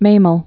(māməl)